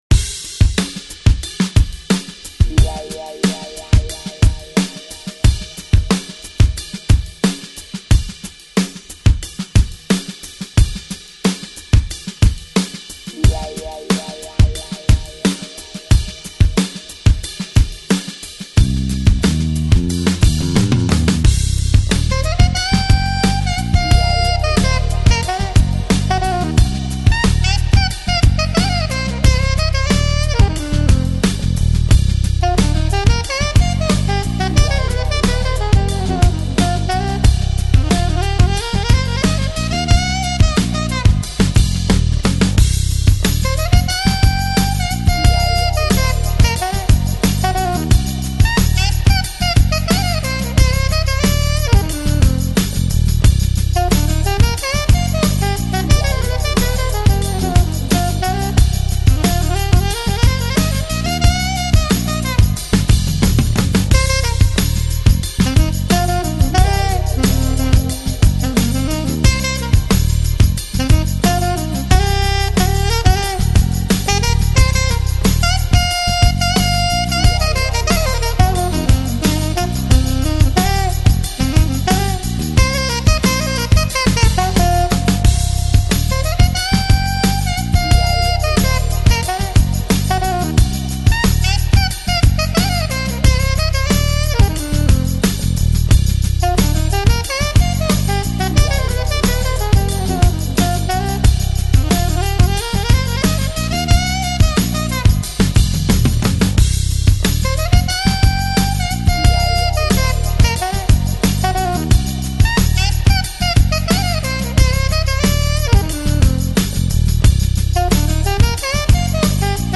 Жанр: Lounge, Chill Out, Jazz